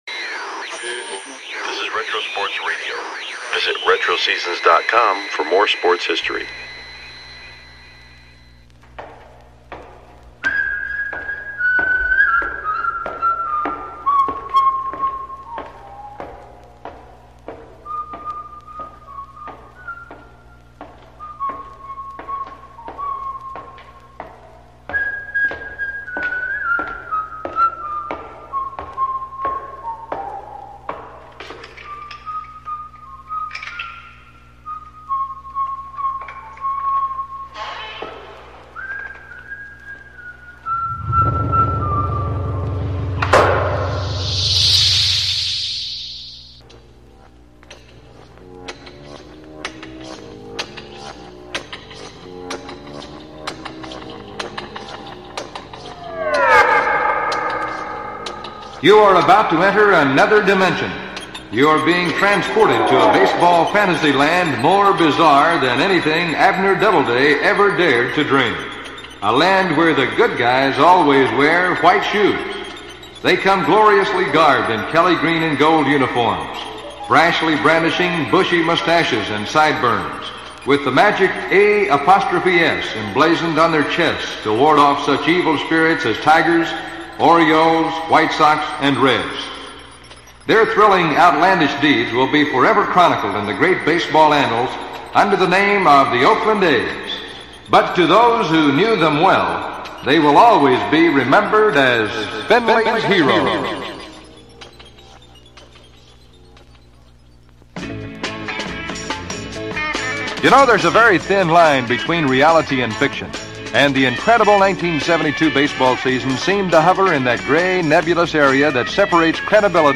1950-Oct-06 - PHI/NYY - World Series G3 - Philadelphia Phillies vs New York Yankees - Classic Baseball Radio Broadcast – Retro Sports Radio: Classic Games from History – Podcast